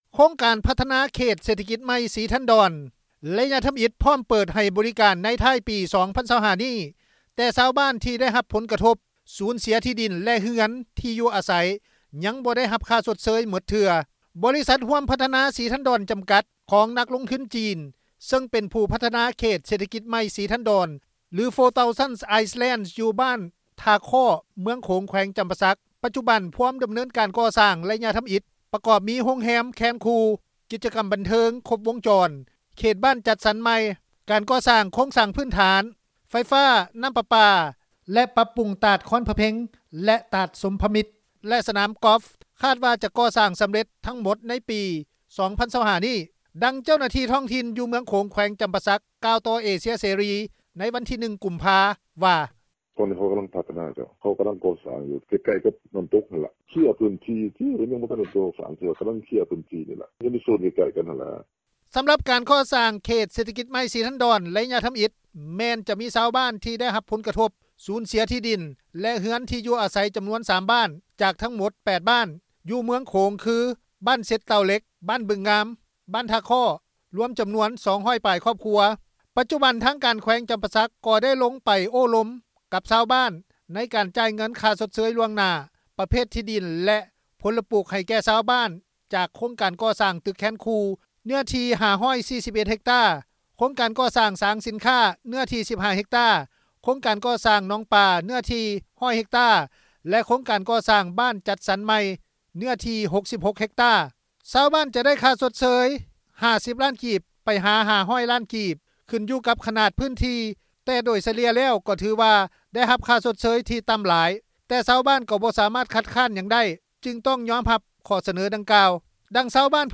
ດັ່ງເຈົ້າໜ້າທີ່ທ້ອງຖິ່ນ ຢູ່ເມືອງໂຂງ ແຂວງຈໍາປາສັກ ໄດ້ກ່າວຕໍ່ວິທຍຸ ເອເຊັຽເສຣີ ໃນວັນທີ 1 ກຸມພາ ວ່າ:
ດັ່ງຊາວບ້ານຜູ້ນຶ່ງ ໃນເມືອງໂຂງ ແຂວງຈໍາປາສັກ ທີ່ສູນເສັຽທີ່ດິນ ແລະ ເຮືອນທີີ່ຢູ່ອາໄສ ກ່າວຕໍ່ວິທຍຸ ເອເຊັຽເສຣີ ໃນມື້ດຽວກັນວ່າ: